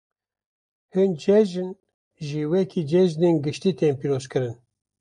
Pronounced as (IPA)
/ɡɪʃˈtiː/